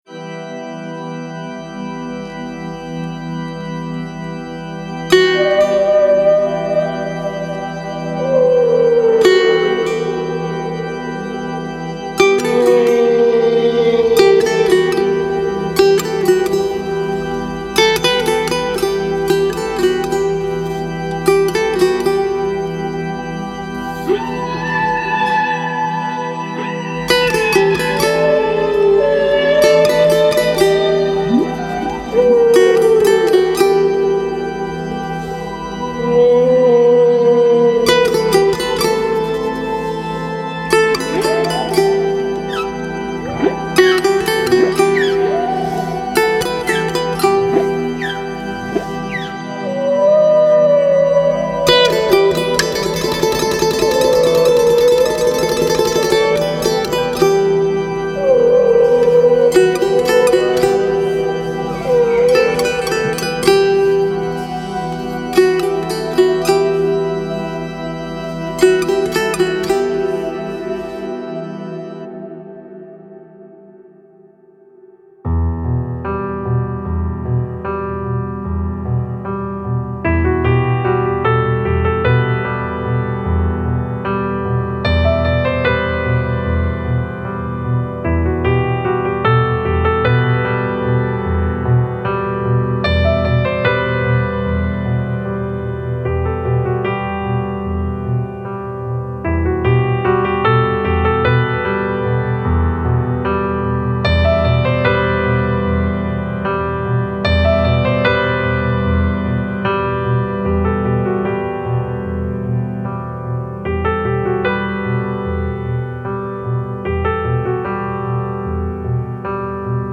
سبک آرامش بخش , پیانو , موسیقی بی کلام